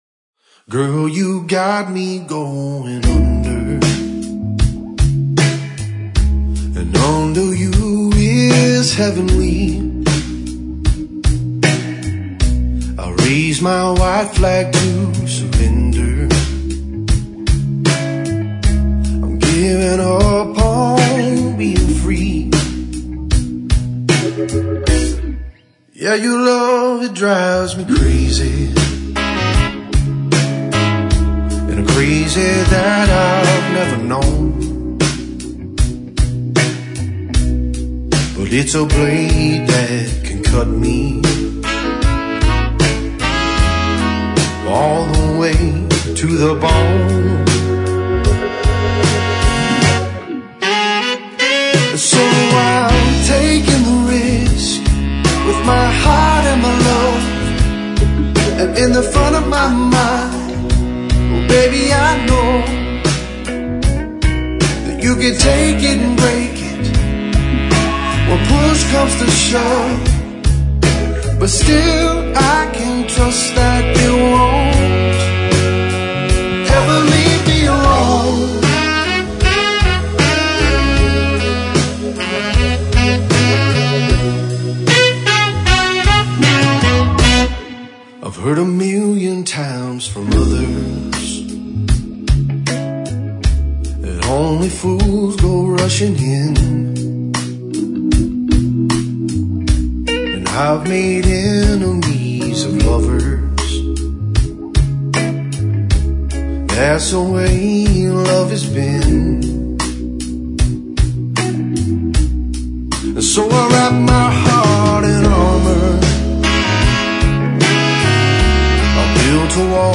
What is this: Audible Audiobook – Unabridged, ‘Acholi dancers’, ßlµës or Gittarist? ßlµës